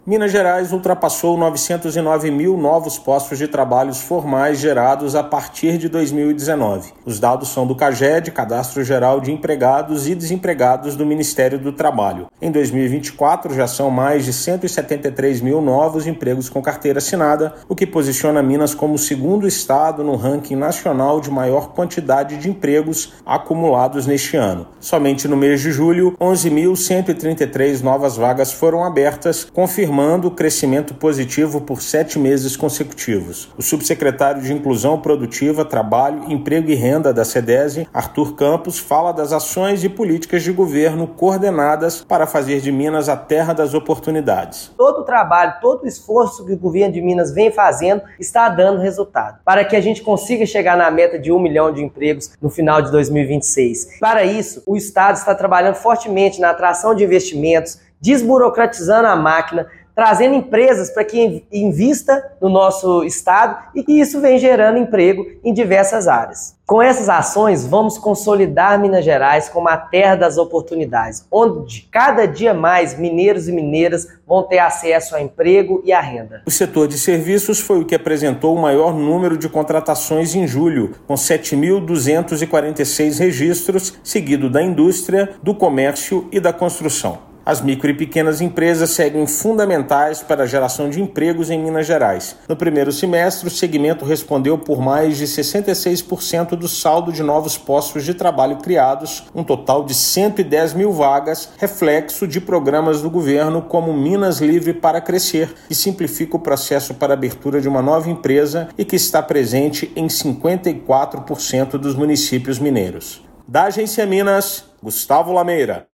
Impulsionado pela força das micro e pequenas empresas, Estado se aproxima da meta de chegar a 1 milhão de postos gerados até 2026. Ouça matéria de rádio.